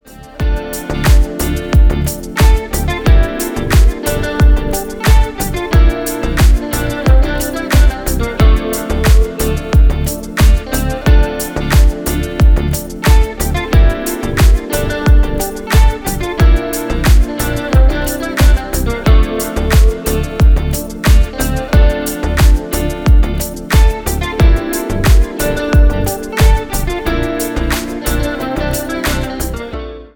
Поп Музыка # Танцевальные
без слов